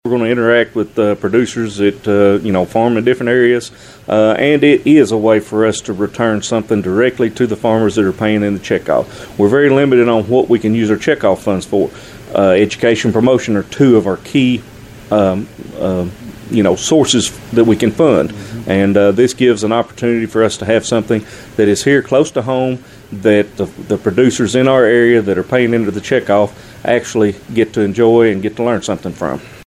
The 20th annual Kentucky Soybean Promotion Day at Murray State University Tuesday provided a reality check, a word of optimism for the future of agriculture, and a look at potential weather influences.